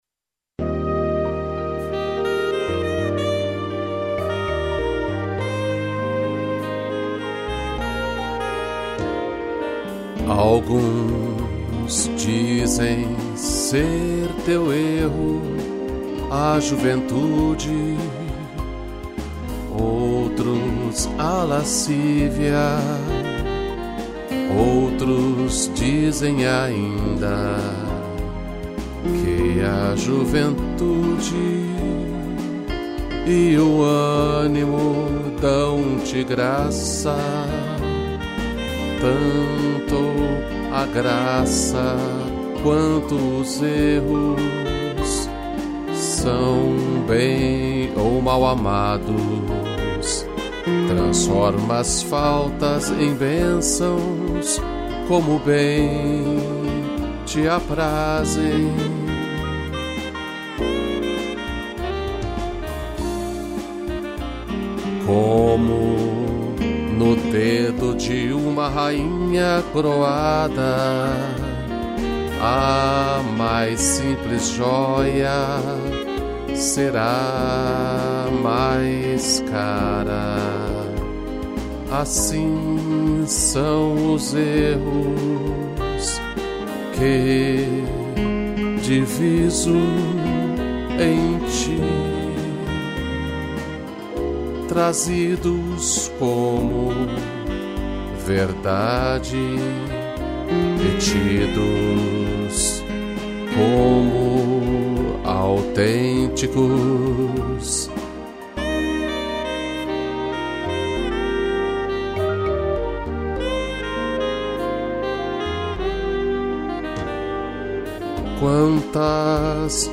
piano, cello, violino e sax